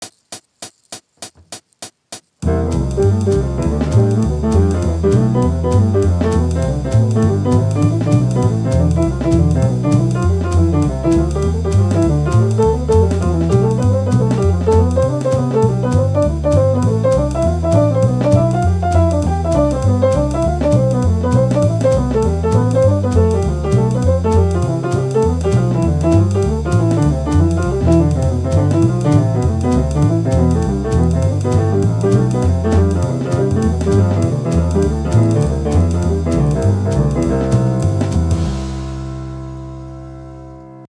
さいごに、ベース・ドラム付きで練習してみましょう（iReal Proというアプリですが）。
アップテンポです！